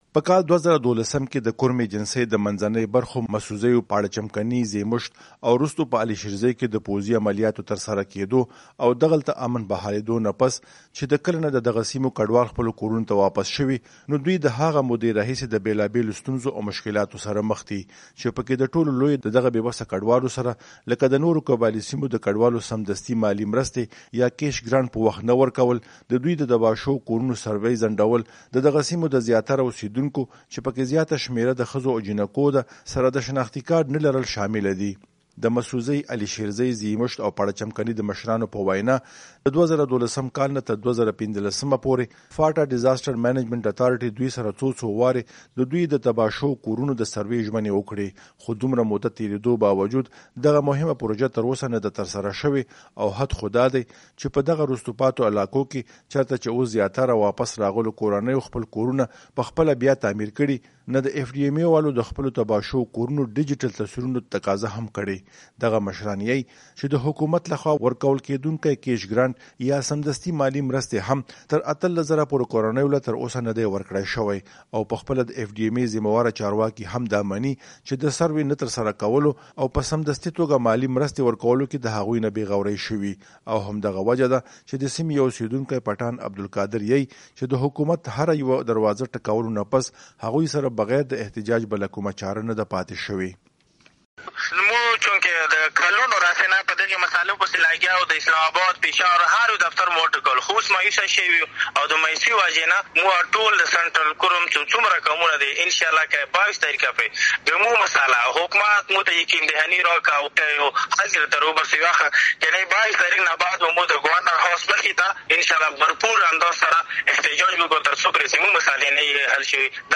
پېښور —